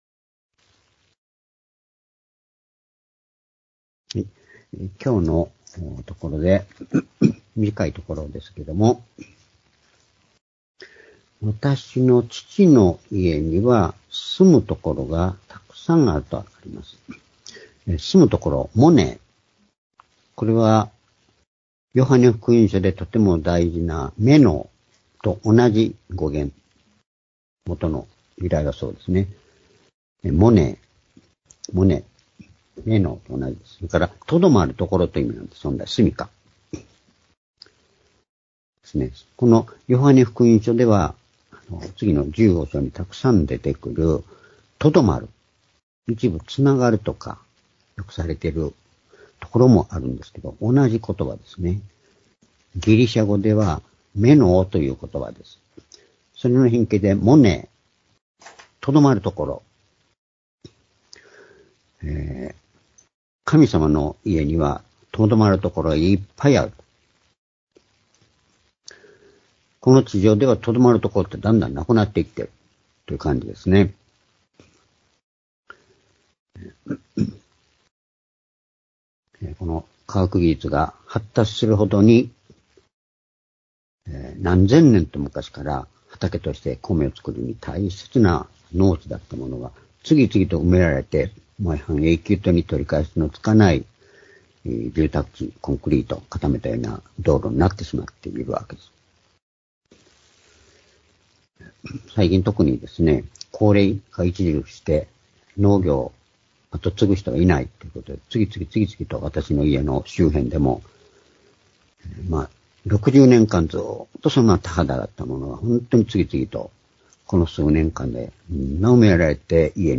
「私のいるところにあなた方もいる」-ヨハネ14の2－3－２０２１年2月１８日（主日礼拝）
主日礼拝日時 ２０２１年2月１８日（主日礼拝） 聖書講話箇所 「私のいるところにあなた方もいる」 ヨハネ14の2－3 ※視聴できない場合は をクリックしてください。